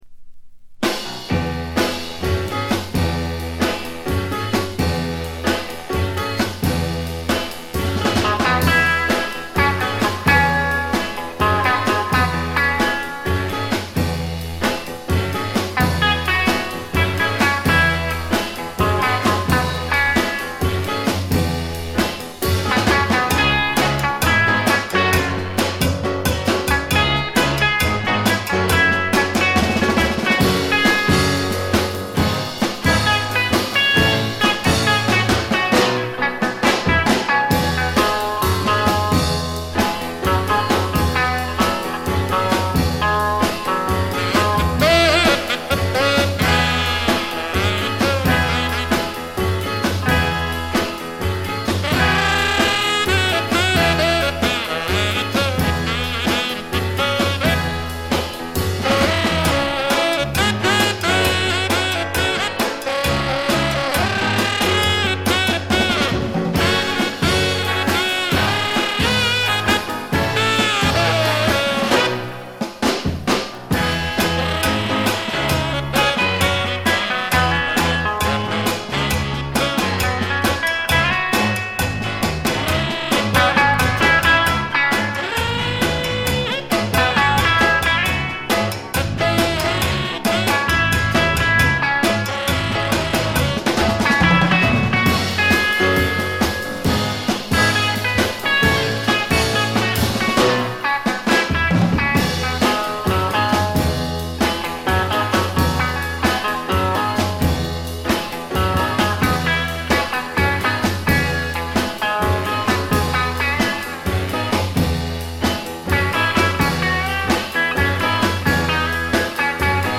わずかなノイズ感のみ。
ぶりぶりで楽しいインスト集。
試聴曲は現品からの取り込み音源です。